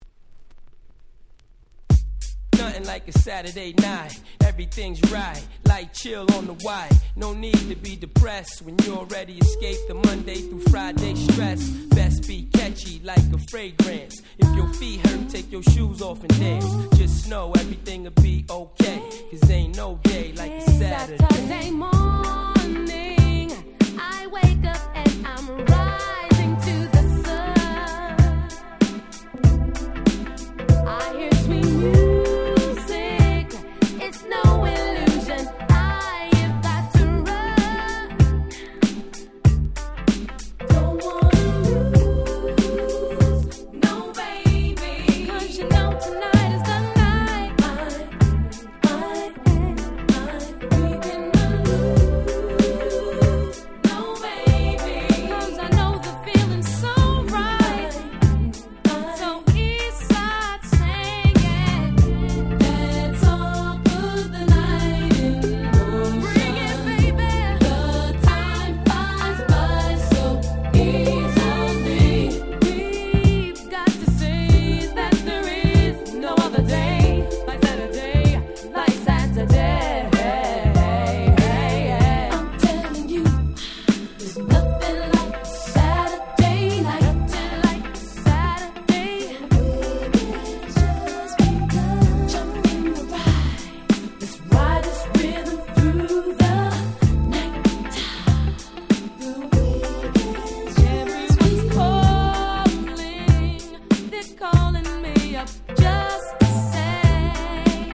97' R&B Classic !!